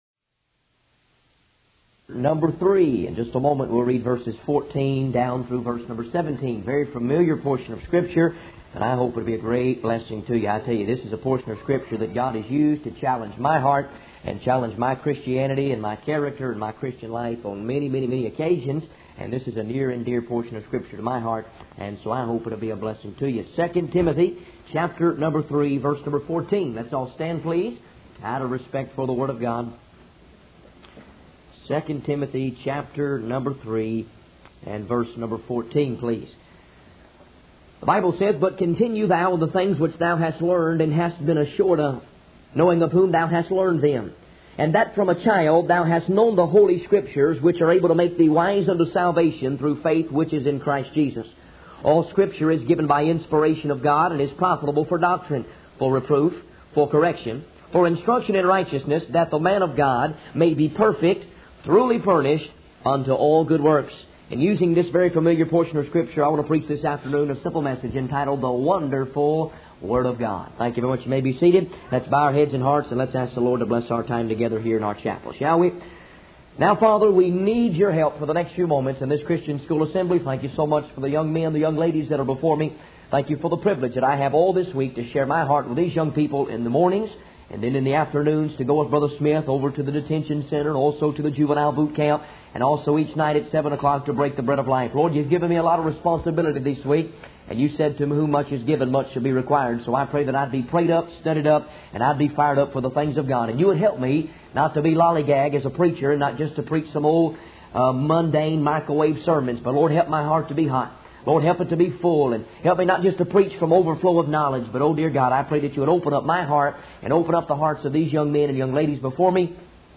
In this sermon, the preacher begins by sharing about his travels and experiences, mentioning places like the natural bridge, Buffalo, Niagara Falls, and California. However, he emphasizes that despite all the amazing things he has seen, nothing compares to the Bible. He then focuses on Joshua 1:8, highlighting the importance of meditating on and obeying the Word of God.